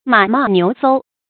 马浡牛溲 mǎ bó niú sōu 成语解释 见“马勃牛溲”。